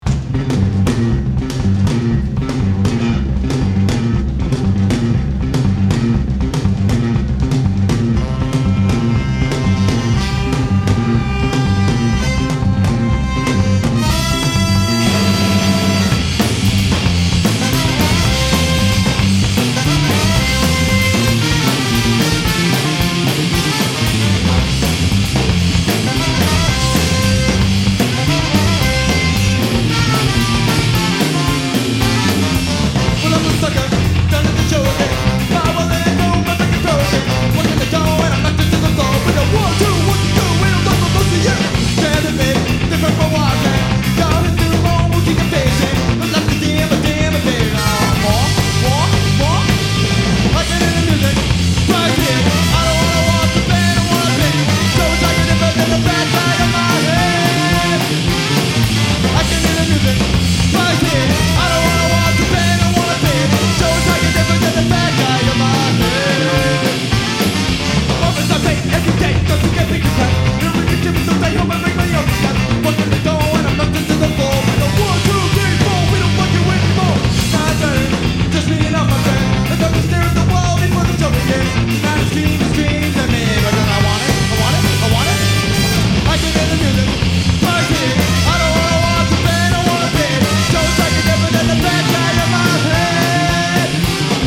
Recorded live at El ‘N’ Gee in Connecticut.